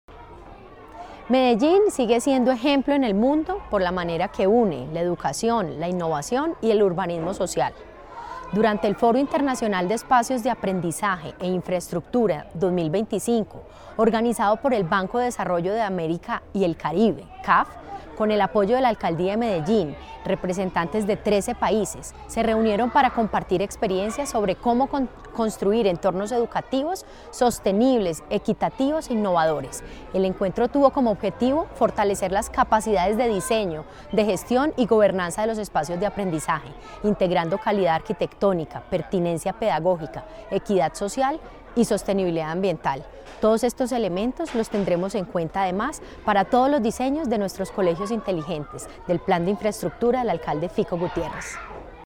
Declaraciones-secretaria-de-Educacion-Carolina-Franco-Giraldo-2.mp3